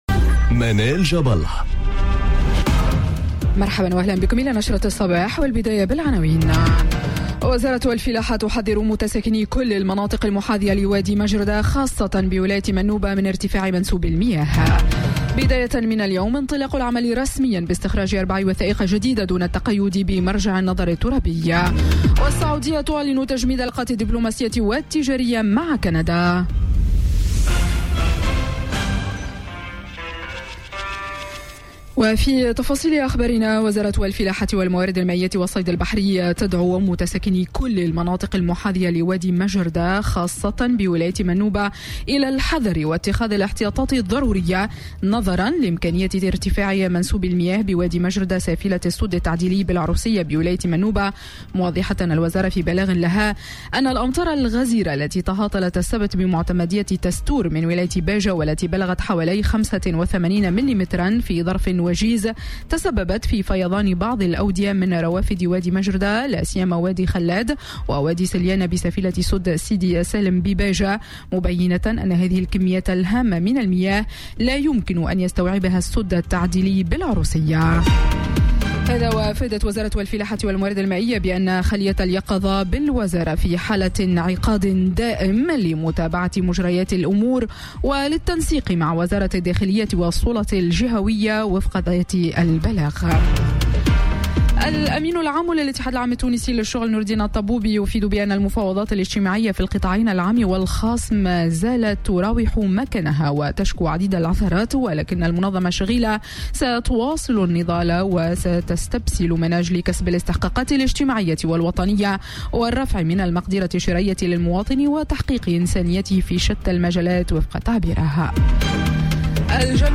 نشرة أخبار السابعة صباحا ليوم الإثنين 6 أوت 2018